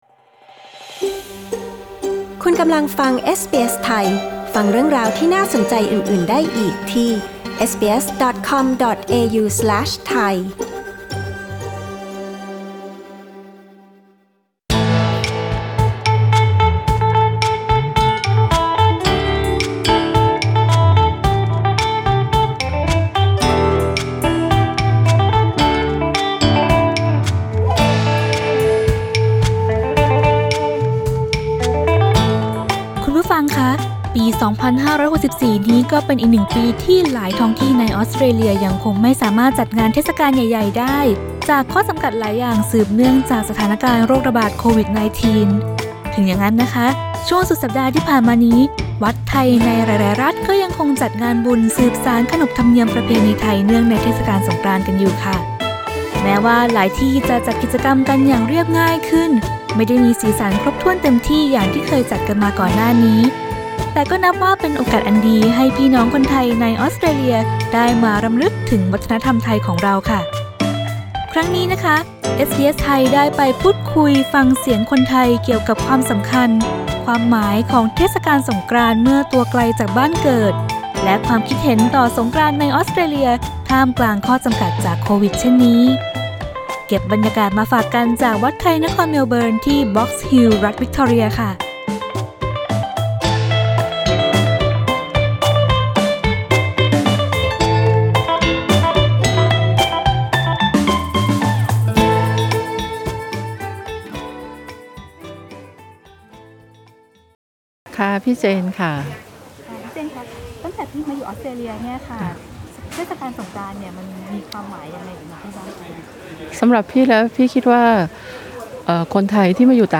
เอสบีเอส ไทย ชวนพูดคุยฟังเสียงคนไทยเกี่ยวกับความสำคัญ ความหมายของเทศกาลสงกรานต์เมื่อตัวไกลจากบ้านเกิด และความคิดเห็นต่อสงกรานต์ในออสเตรเลียท่ามกลางข้อจำกัดจากโควิด-19 เช่นนี้